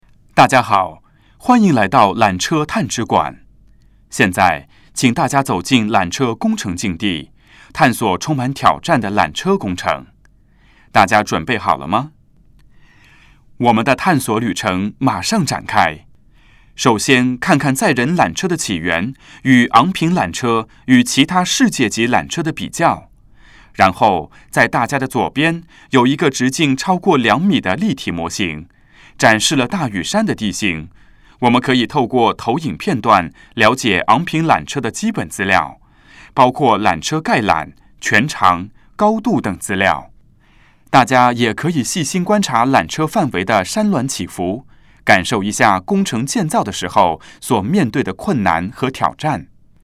缆车探知馆语音导赏 (普通话)